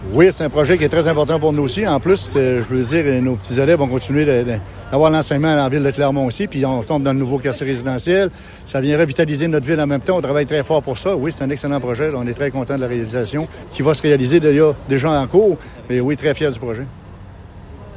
Conférence de presse concernant la construction de la nouvelle école primaire de Clermont.
Luc Cauchon, maire, Ville de Clermont